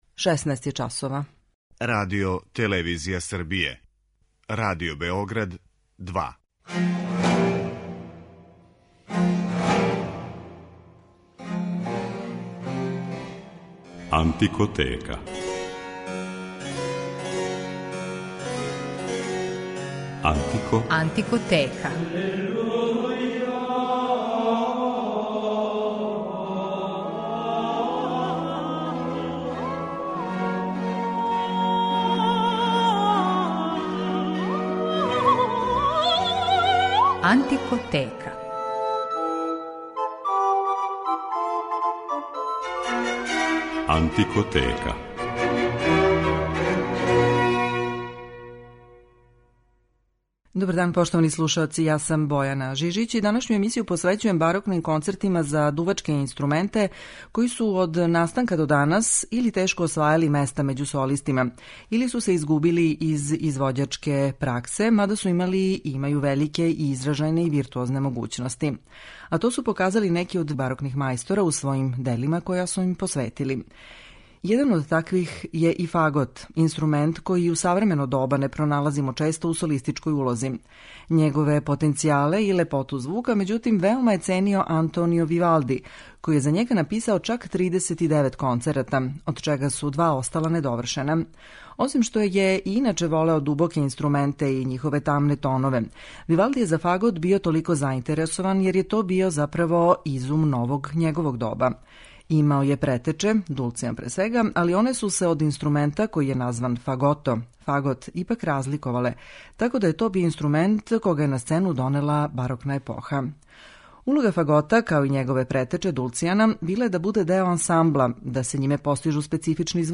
Барокни концерти за необичне дуваче
Барокним концертима за дувачке инструменте који су или ретко добијали солистичке улоге или су се сасвим изгубили из извођачке праксе, иако су имали и имају врелике и изражајне и виртозне могућности, посвећена је данашња емисија.
концерте за фагот, обоу д`аморе, ловачки рог и шалмај
Тромба марину.